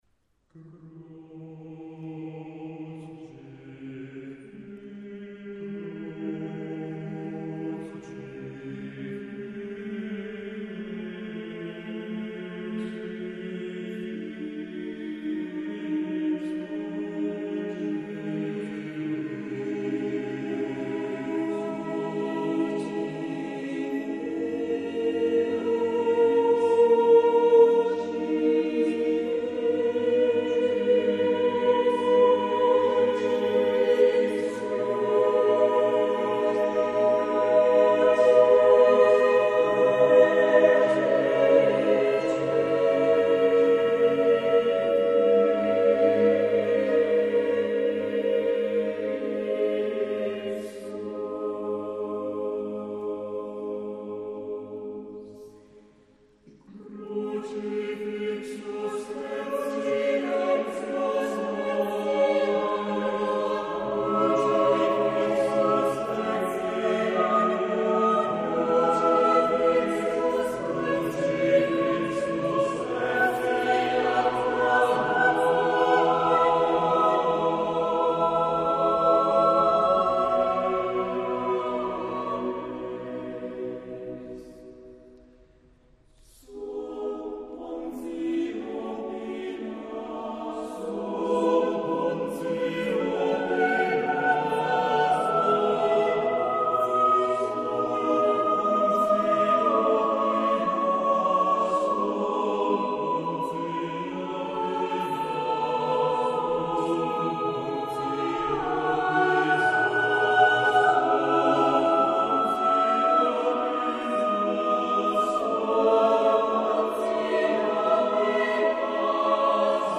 CD2 POLIFONIA A CAPPELLA